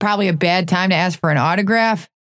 Vyper voice line - Probably a bad time to ask for an autograph?